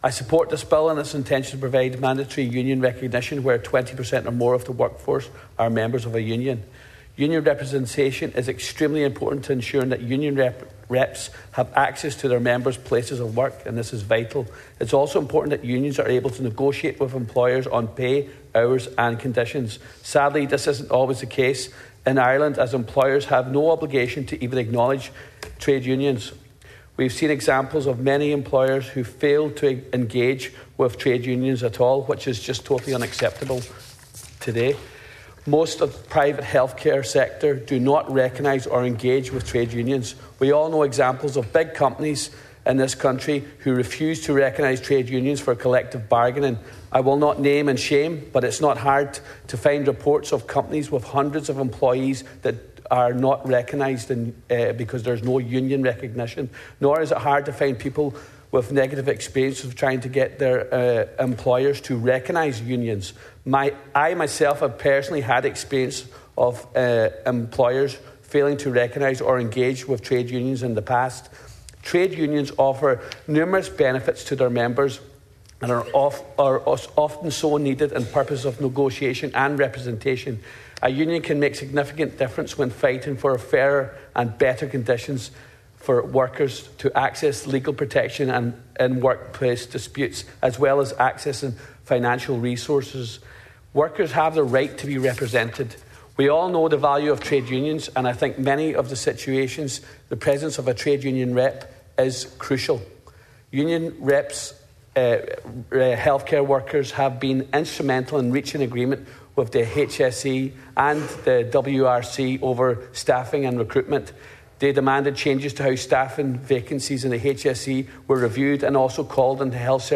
That’s according to Donegal Deputy Charles Ward, who was speaking in the Dail in support of the Trade Union Recognition Bill.